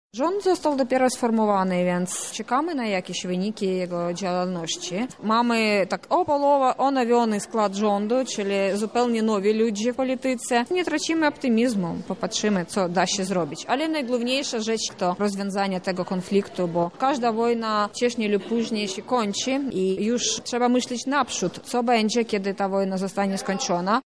Seminarium naukowe zostało zorganizowane przez Centrum Europy Wschodniej UMCS, Wydział Politologii UMCS oraz Polskie Towarzystwo Nauk Politycznych.